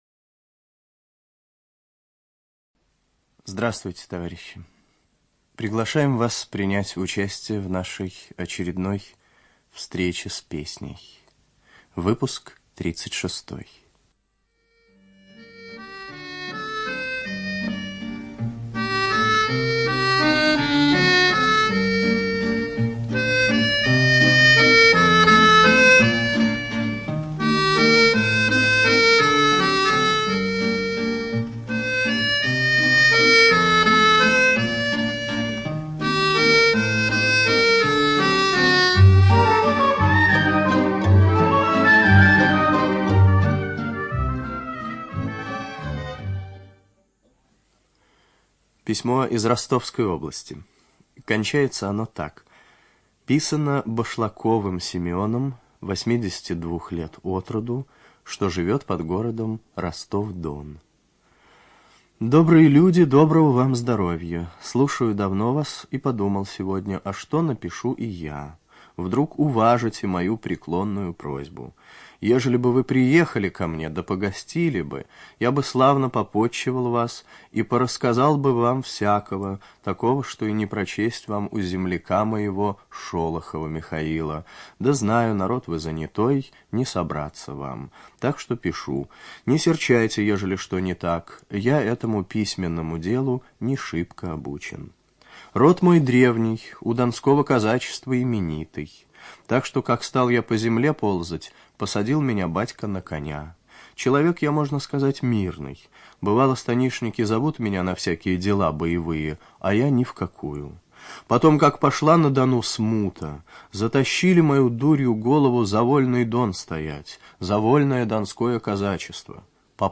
ЧитаетТатарский В.
ЖанрРадиопрограммы